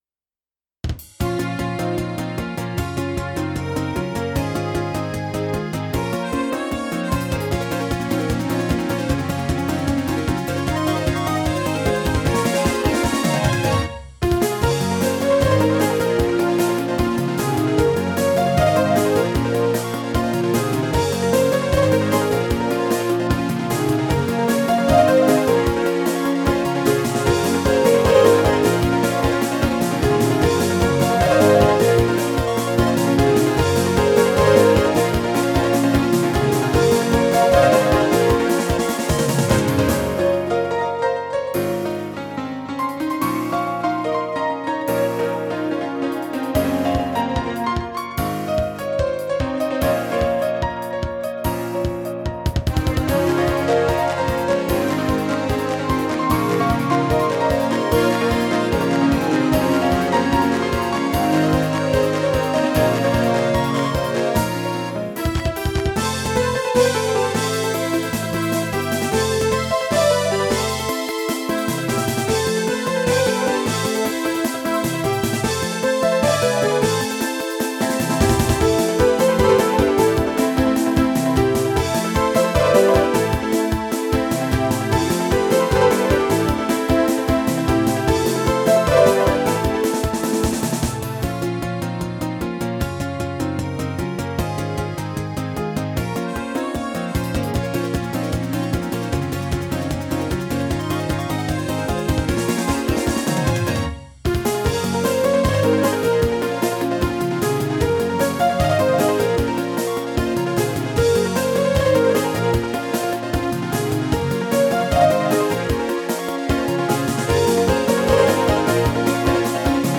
由Roland Sound Canvas 88Pro实机录制